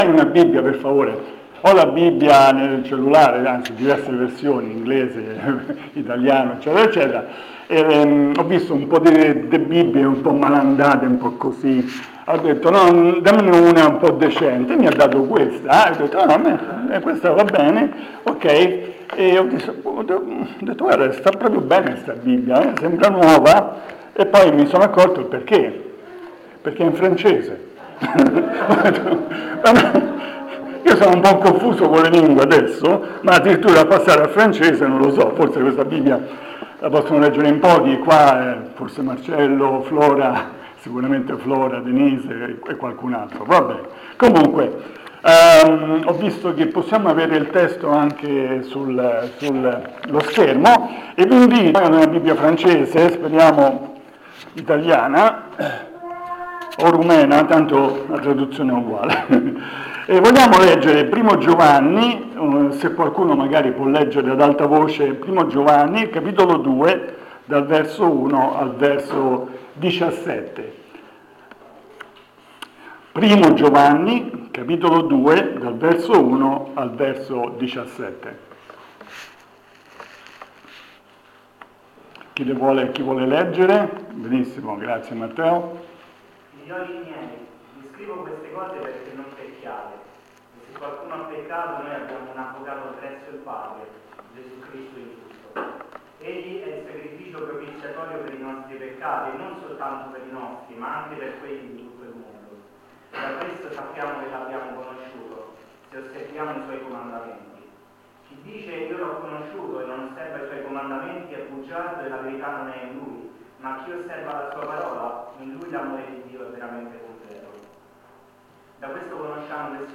Insegnamenti del 13/04/2025 sul brano di 1 Giovanni 2:1-17.